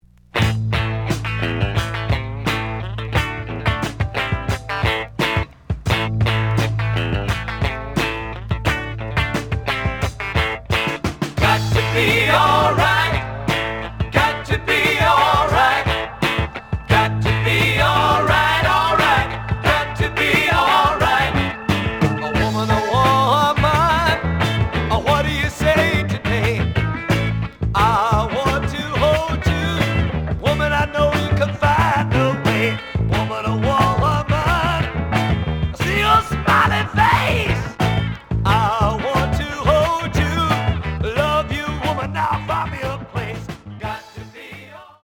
The audio sample is recorded from the actual item.
●Format: 7 inch
●Genre: Funk, 70's Funk